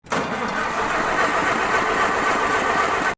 Tractor Won't Start
SFX
Tractor Won't Start.mp3